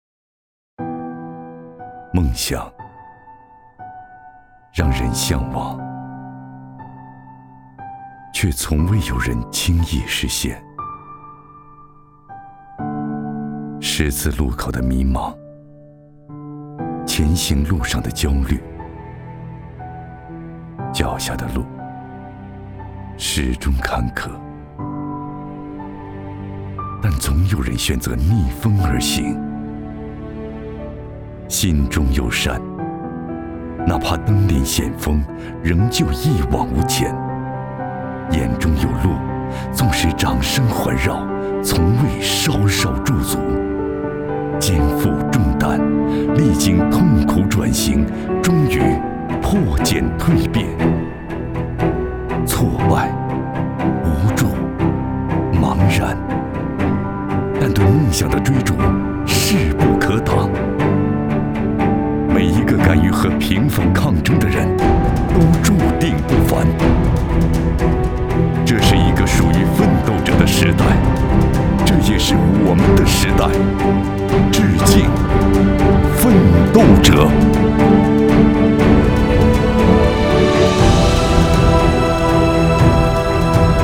男国303_其他_微电影_致敬奋斗者.mp3